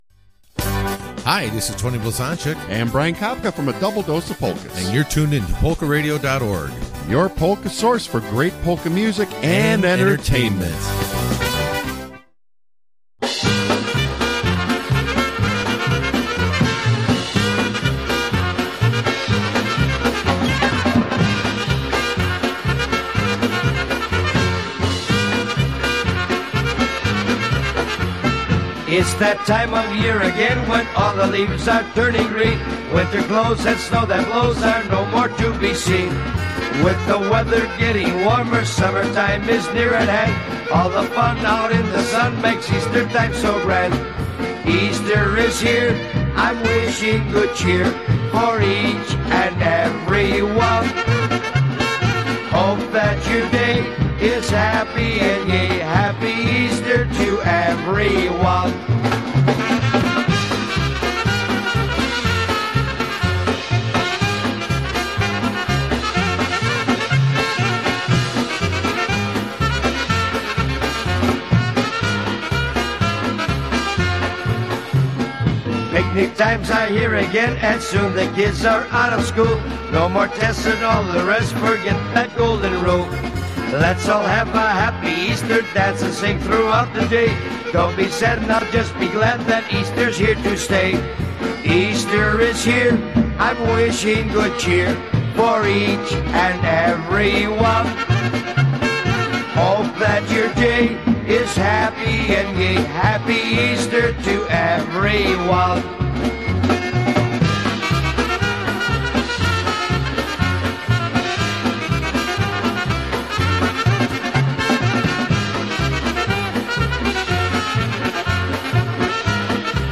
A Polka Show Not for the Faint of Heart!